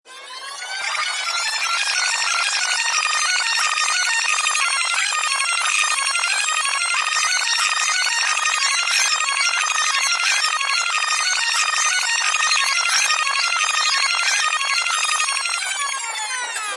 Download Rewind sound effect for free.